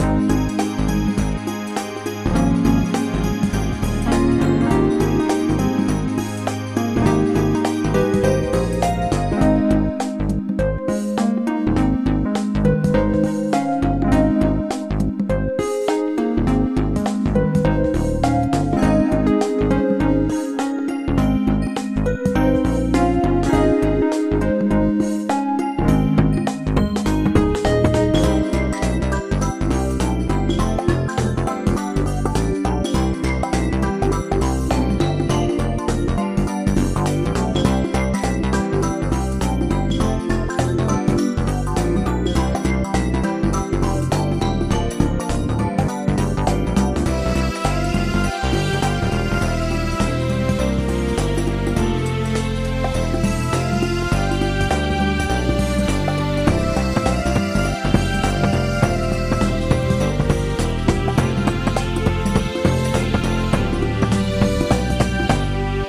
a fun little tune plays